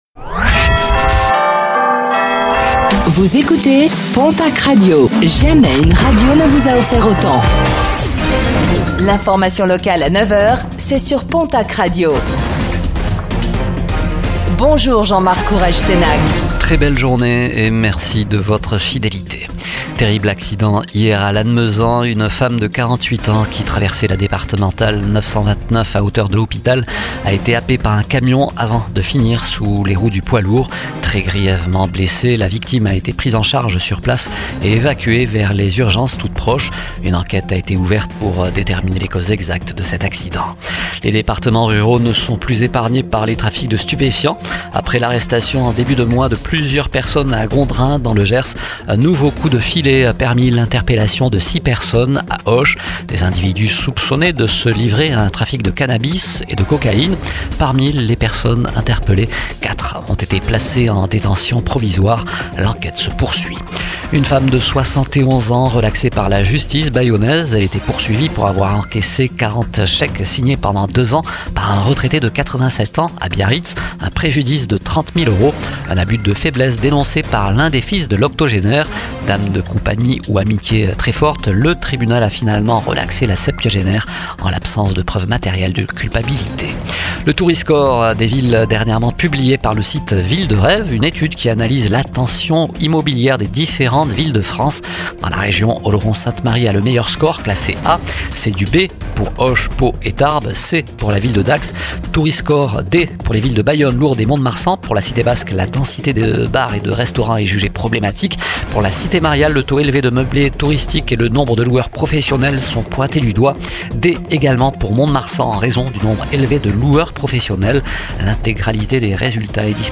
Réécoutez le flash d'information locale de ce mercredi 25 juin 2025